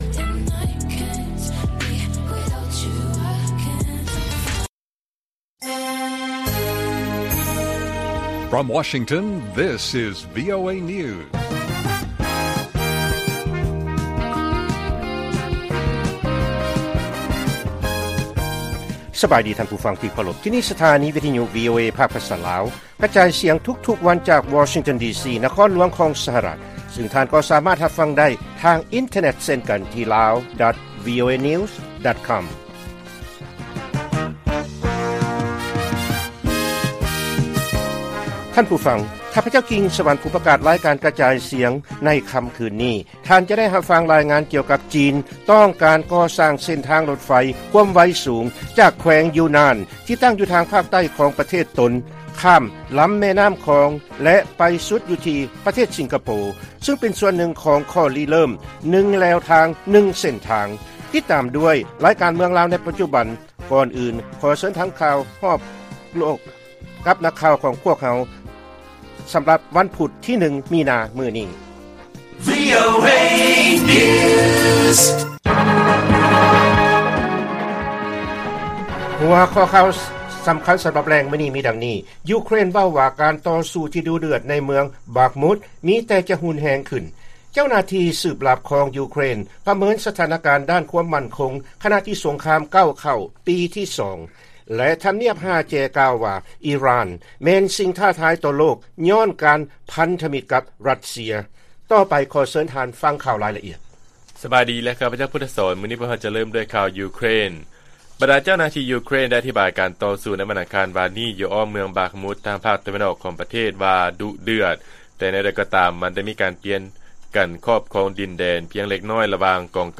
ລາຍການກະຈາຍສຽງຂອງວີໂອເອ ລາວ: ຢູເຄຣນ ເວົ້າວ່າ ການຕໍ່ສູ້ທີ່ດຸເດືອດໃນເມືອງ ບາກມຸດ ມີແຕ່ຈະຮຸນແຮງຂຶ້ນ